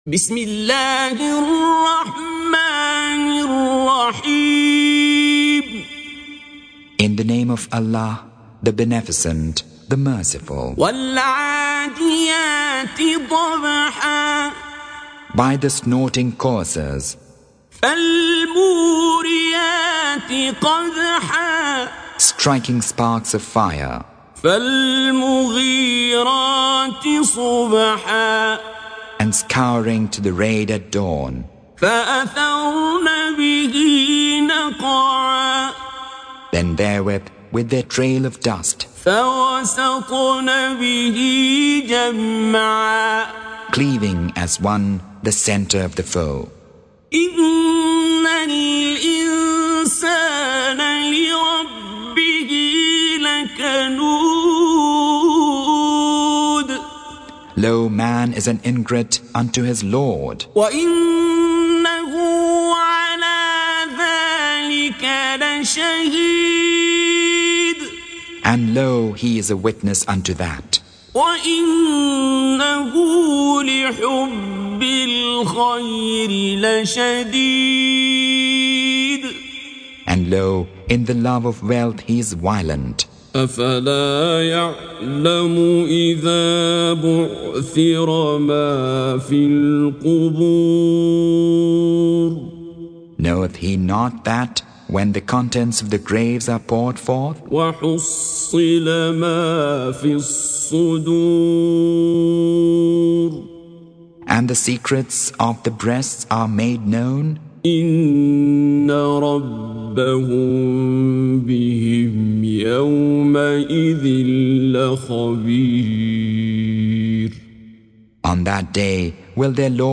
Surah Sequence تتابع السورة Download Surah حمّل السورة Reciting Mutarjamah Translation Audio for 100. Surah Al-'Adiy�t سورة العاديات N.B *Surah Includes Al-Basmalah Reciters Sequents تتابع التلاوات Reciters Repeats تكرار التلاوات